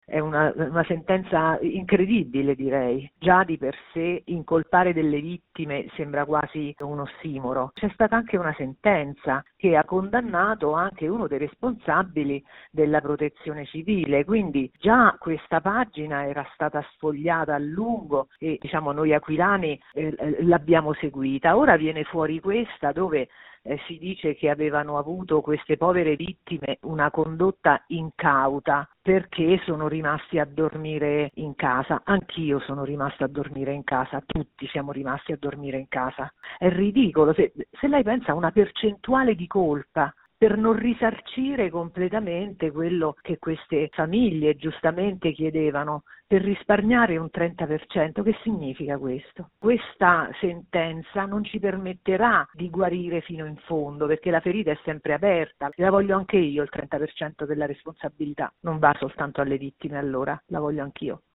Il racconto della giornata di mercoledì 12 ottobre 2022 con le notizie principali del giornale radio delle 19.30. Il nuovo Governo è ancora lontano da una formazione certa e le richieste dei leader sembrano inarrivabili.